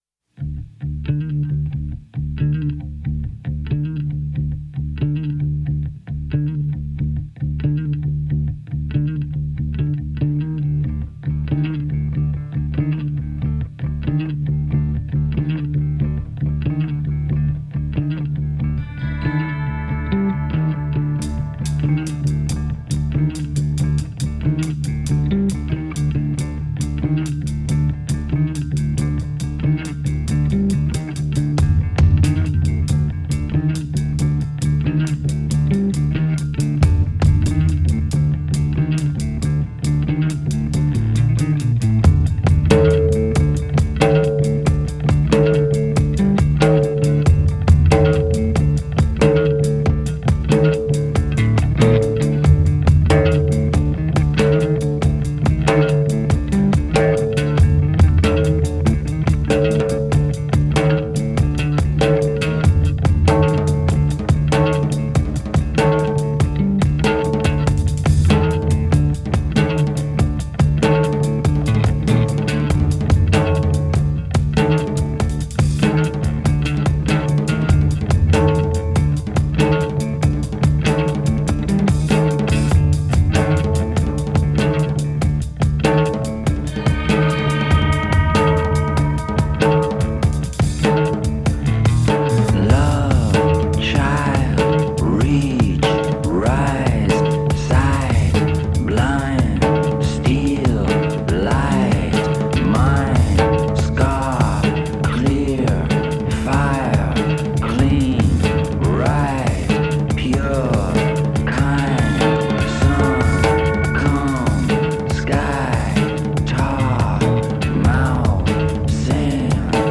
shamanistic